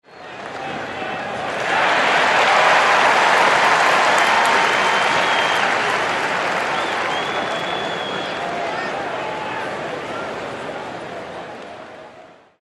Звуки хоккея
Радостный гул болельщиков на хоккейном матче